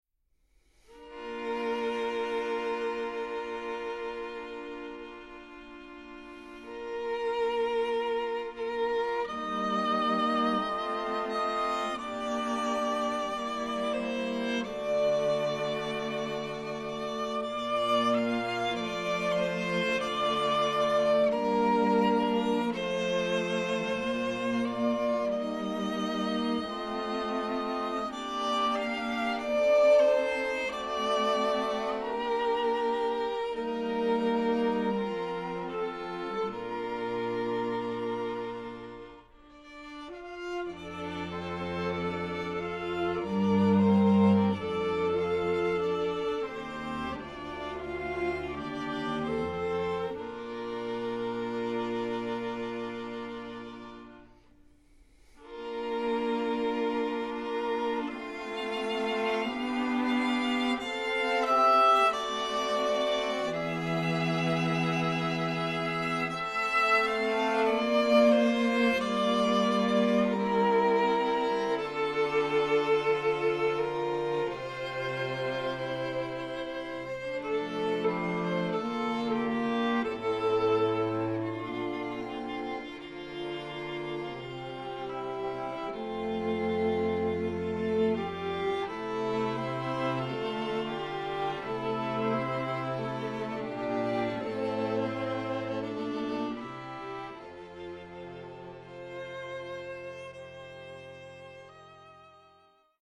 Voicing: 4 Strings